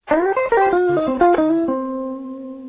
Blues/Rock Riffs
Lick 2 (passend zu C-Blues/Rock) -